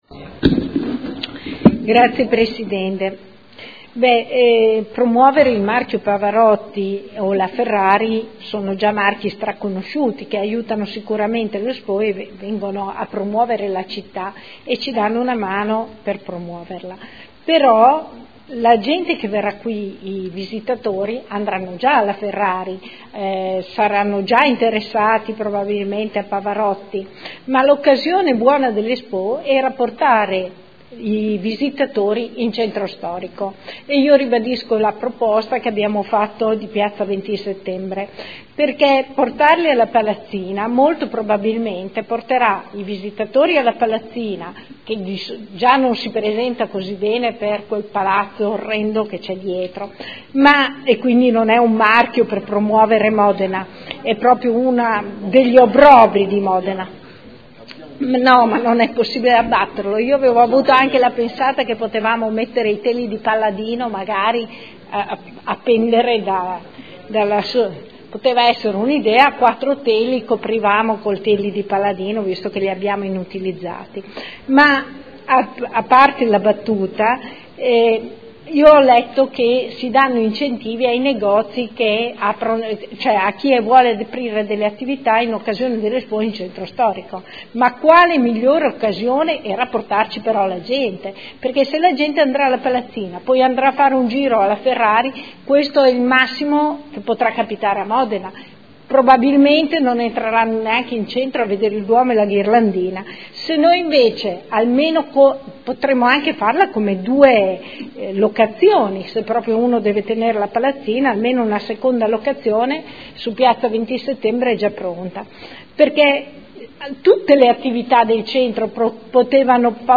Luigia Santoro — Sito Audio Consiglio Comunale
Seduta del 09/02/2015. Dibattito sugli ordini del giorno/mozioni inerenti l'expo 2015